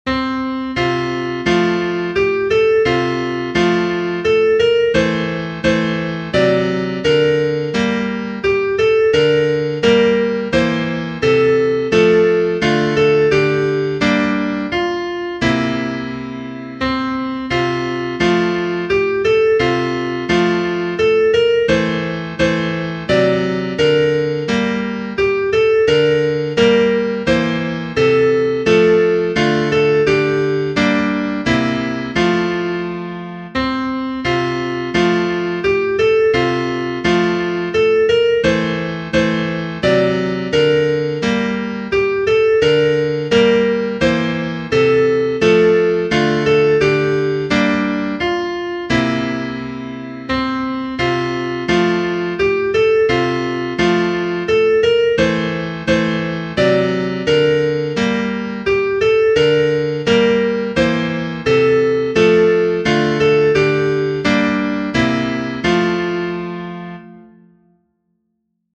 Kirkpatrick, W. J. Genere: Religiose Text by anonymous Away in a manger, no crib for a bed, The little Lord Jesus laid down His sweet head.